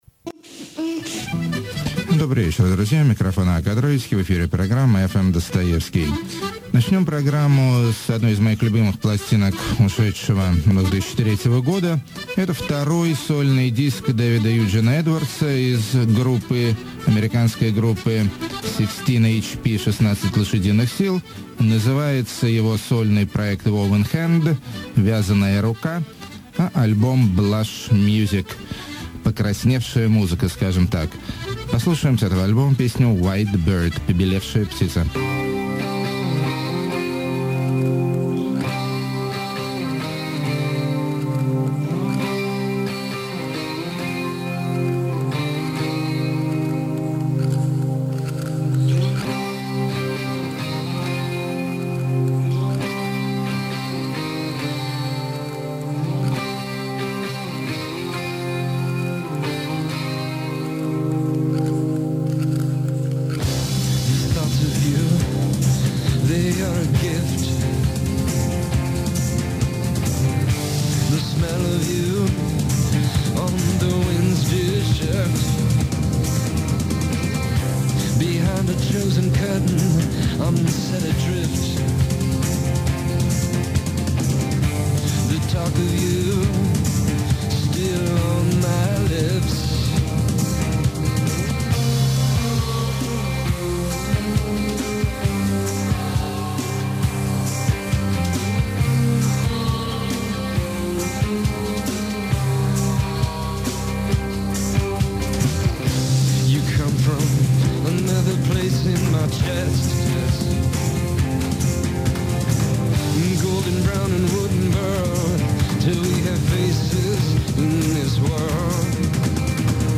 infantile beachy electronica
sexy neo bossa
self-made sounding objects
basic channel-ish noise-dub
part indie pop, part doom metal
beautiful dark minimalism
great garage screaming!
quintessential english melacholia
cabaret-style war songs
topical deep downtempo